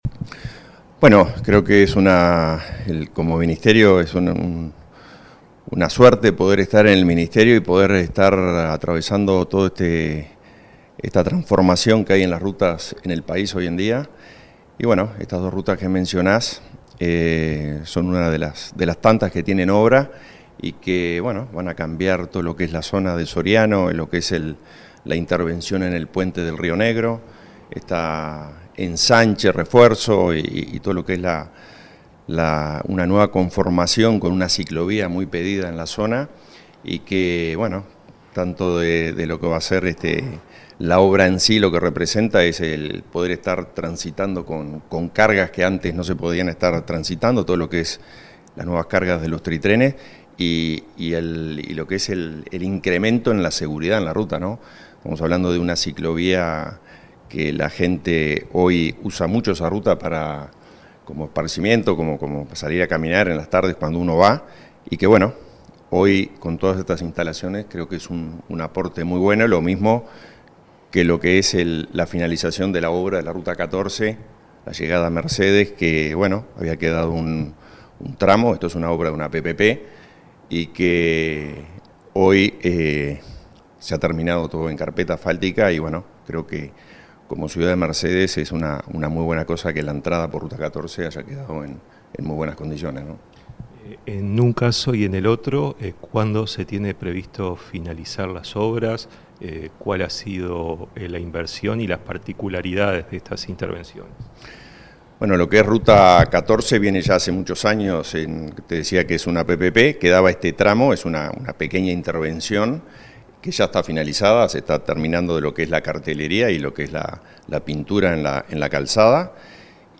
Entrevista al director nacional de Vialidad, Hernán Ciganda